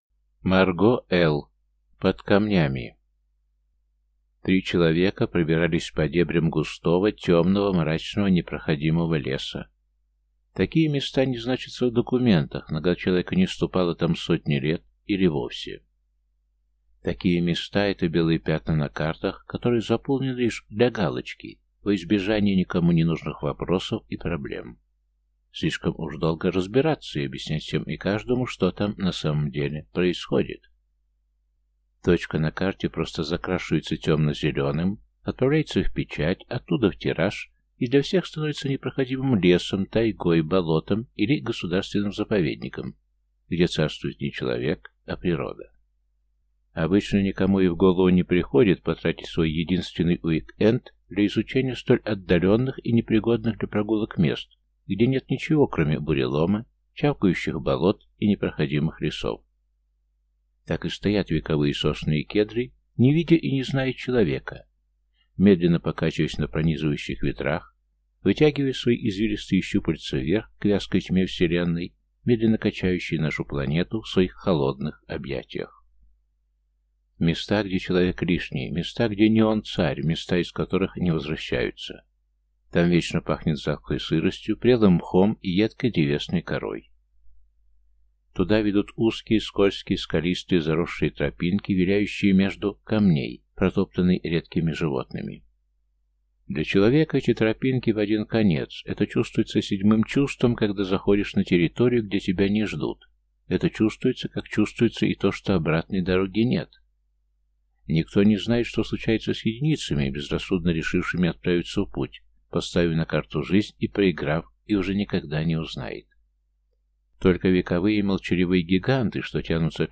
Аудиокнига Под камнями | Библиотека аудиокниг